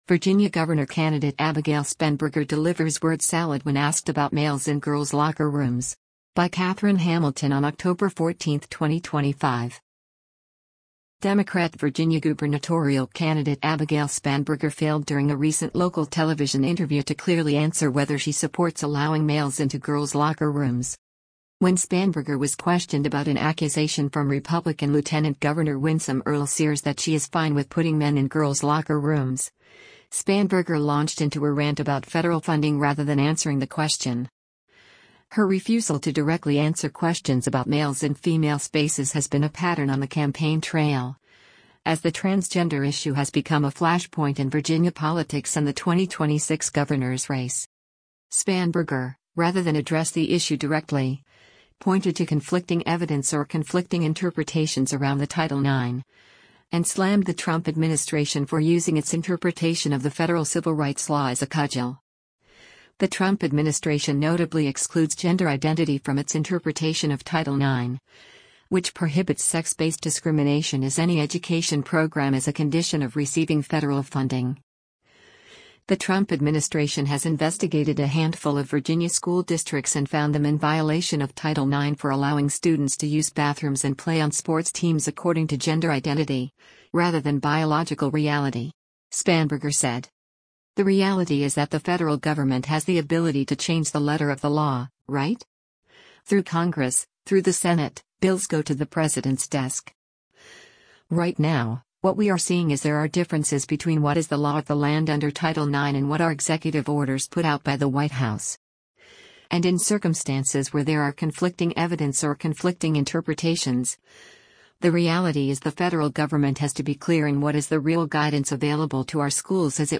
Democrat Virginia gubernatorial candidate Abigail Spanberger failed during a recent local television interview to clearly answer whether she supports allowing males into girls’ locker rooms.